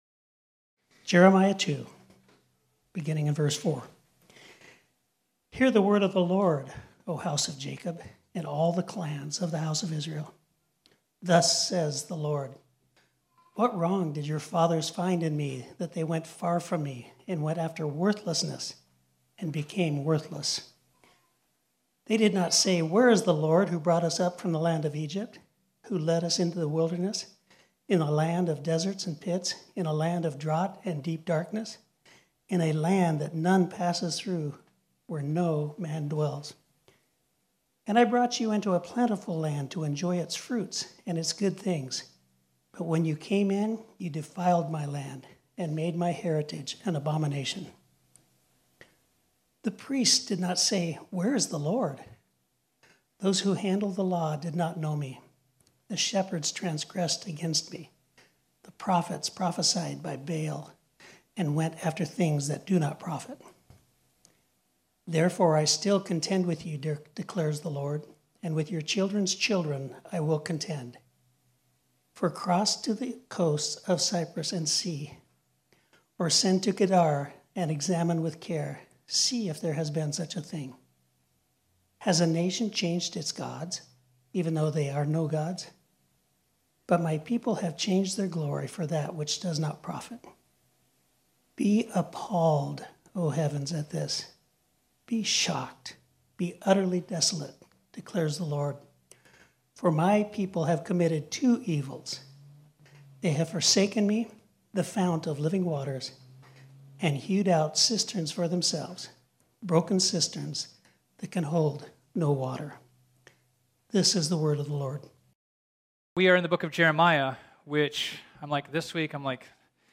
This sermon was originally preached on Sunday, May 1, 2022.